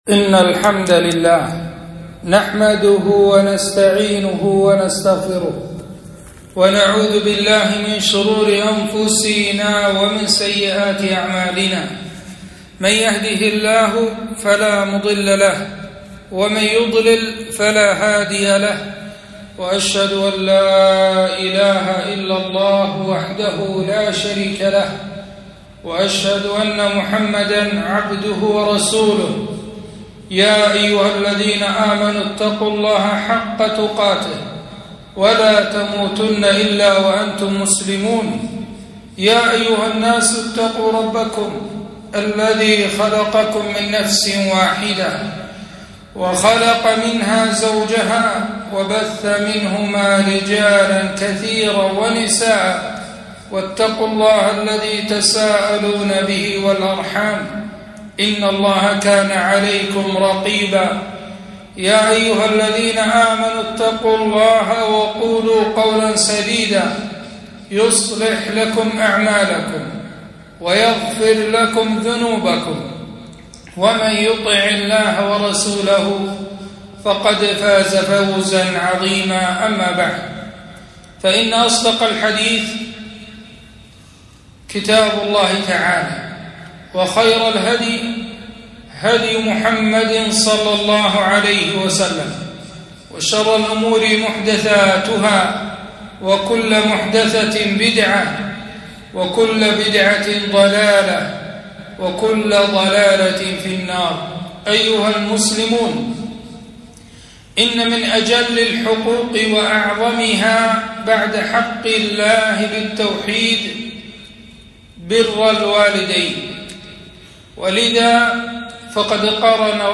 خطبة - فضائل بر الوالدين